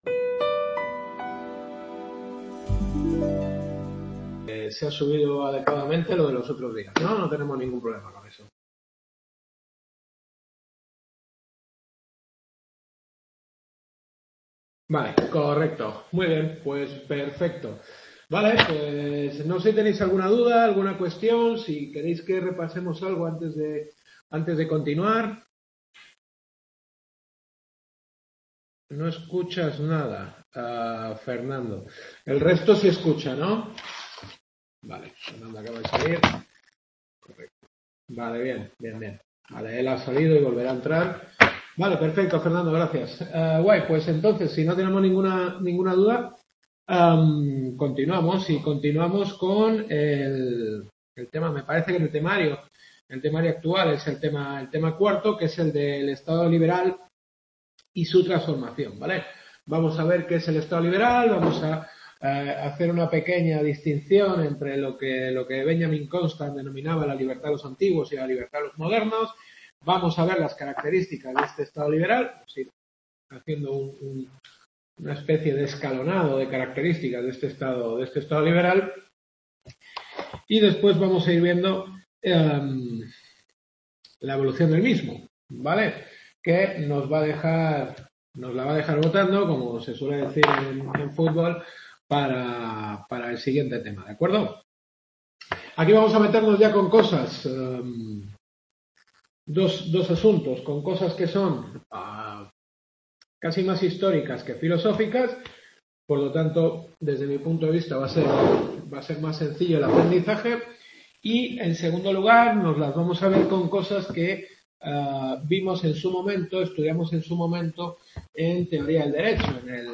Quinta clase.